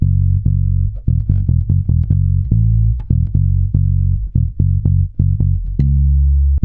-MM RAGGA F#.wav